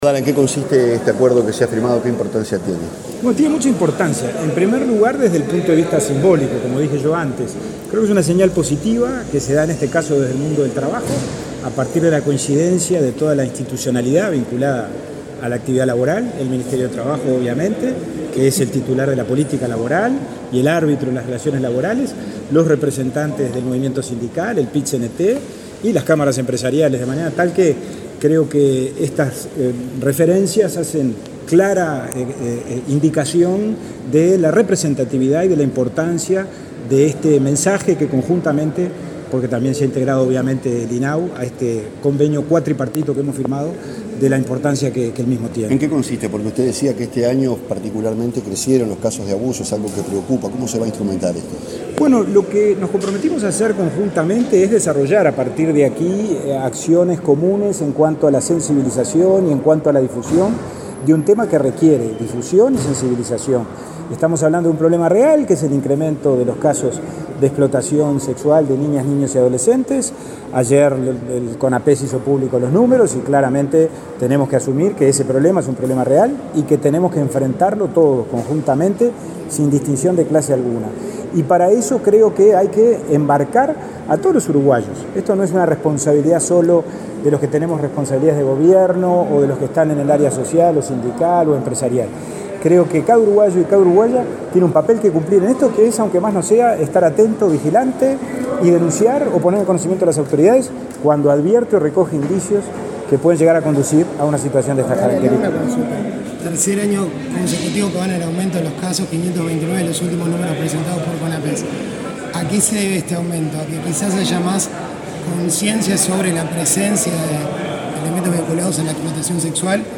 Declaraciones del presidente del INAU, Pablo Abdala
Luego Abdala dialogó con la prensa.